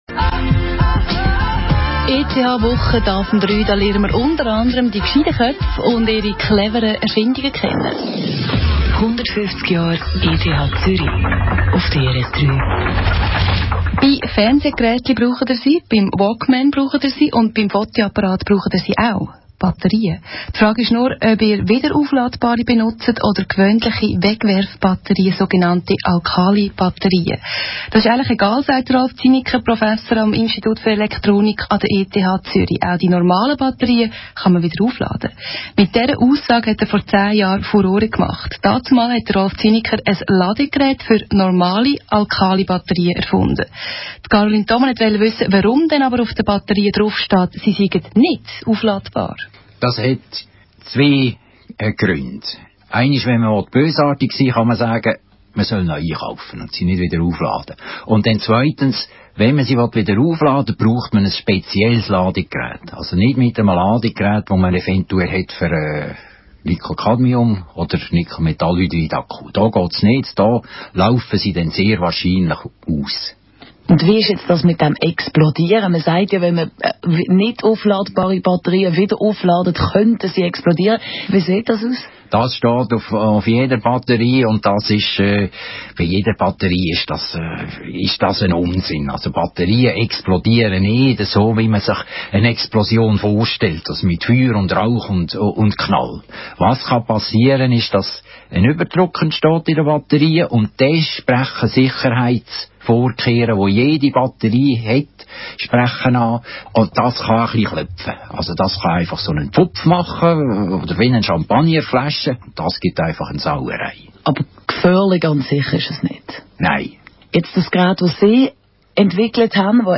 Batterien regenerieren im Radio